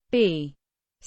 alphabet char sfx